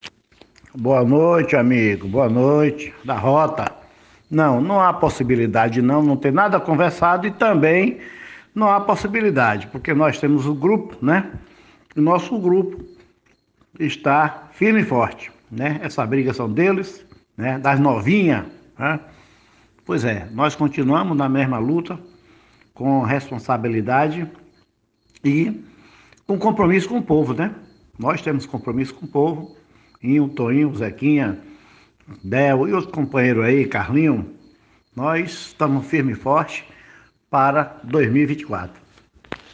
no Programa Rota da Informação na Rádio Rota News Web